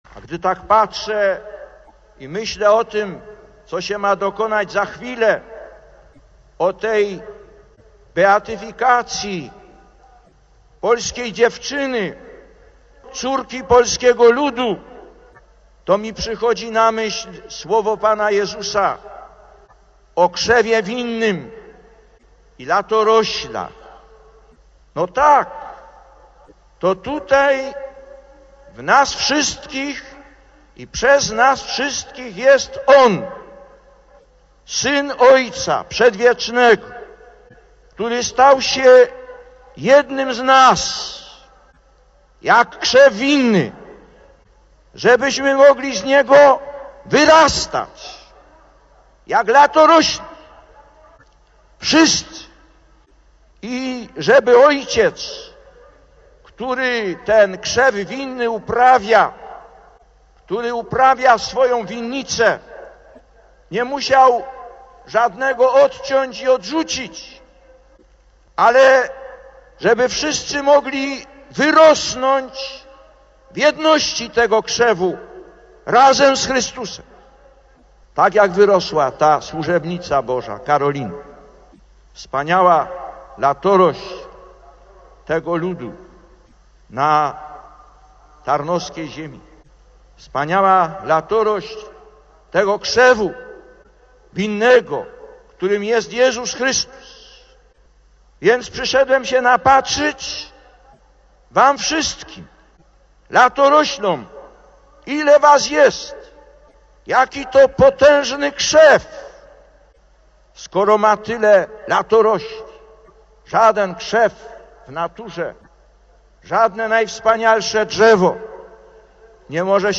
Lektor: Ze słowa do wiernych(Tarnów, 10 czerwca 1987